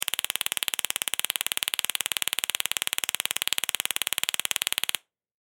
На этой странице собраны звуки электрошокера – от резких разрядов до характерного жужжания.
Звук электрошокера для отпугивания собак